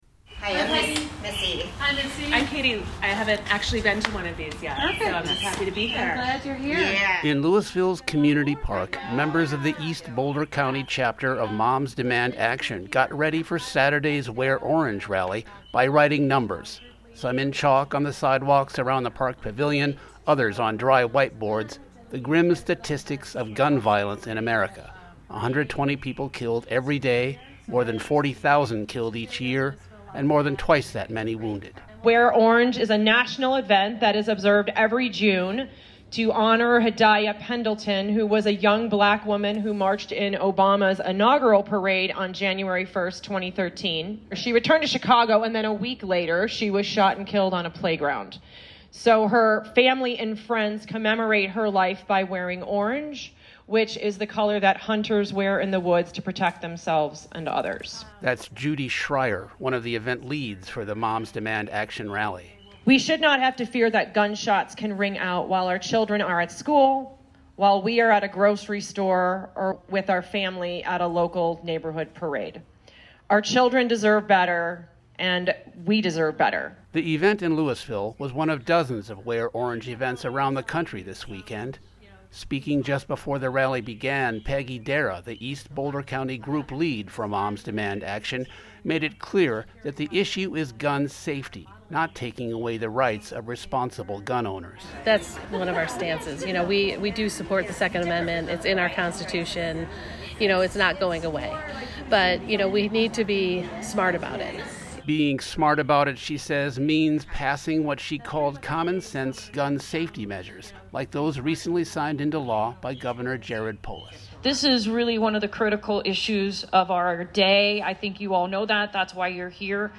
Wear_Orange_Louisville_NEW.mp3